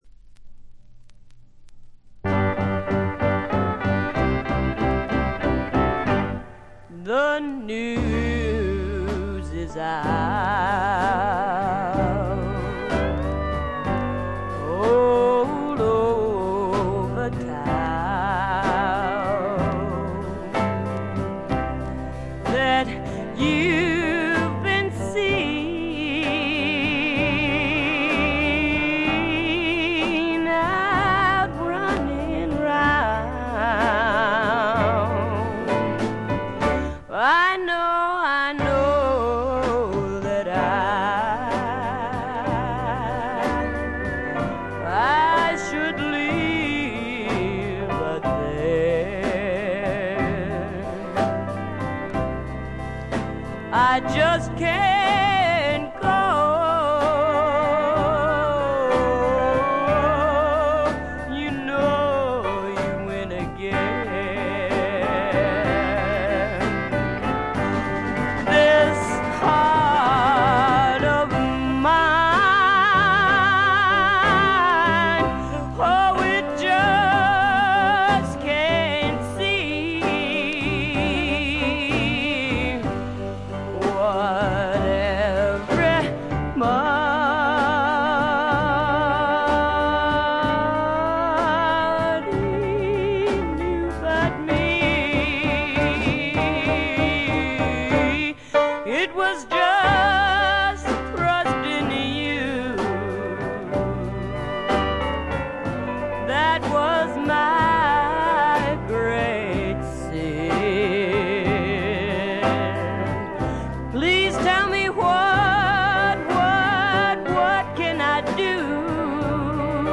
部分試聴ですが、軽微なチリプチ、バックグラウンドノイズ程度。
試聴曲は現品からの取り込み音源です。